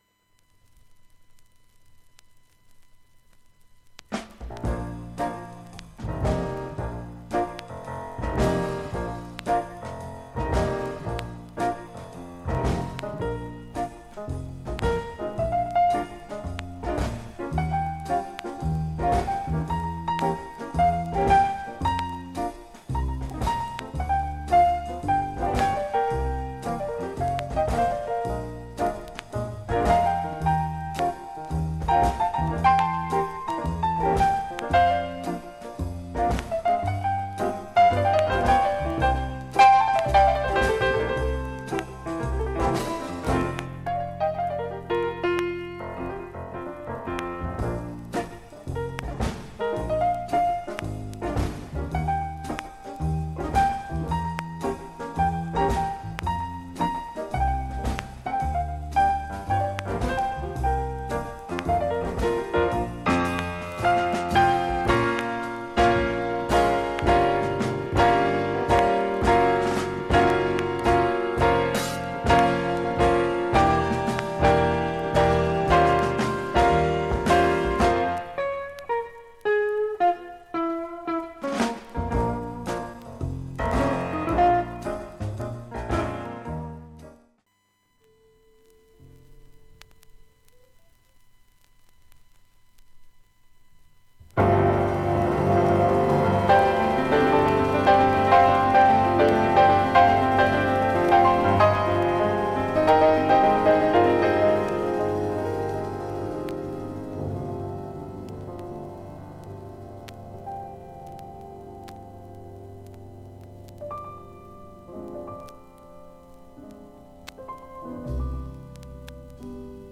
３９回プツ出ますがわずかな感じです。
現物の試聴（上記）できます。音質目安にどうぞ
ほか３回までのわずかなプツ３か所程度
クリアな音質で良好全曲試聴済み。
DEEP GROOVE両面 MONO